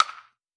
Bat Hit Wood Distant.wav